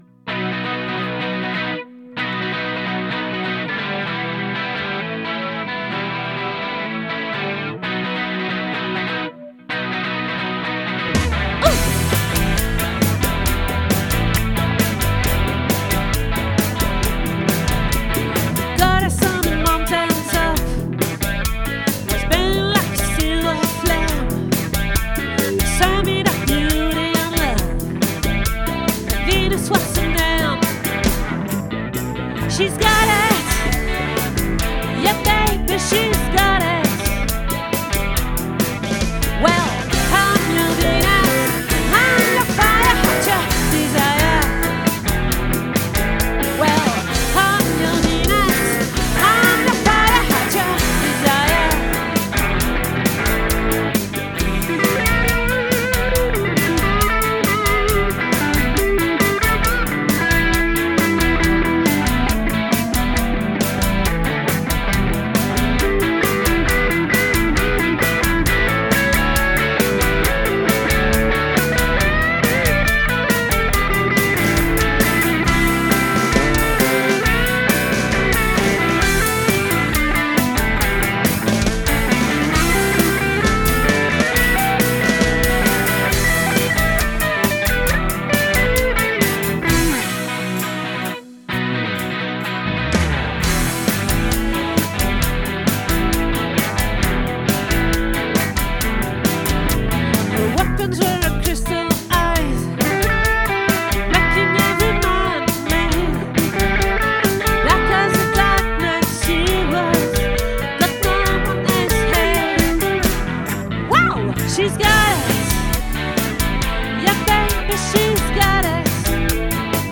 🏠 Accueil Repetitions Records_2024_12_09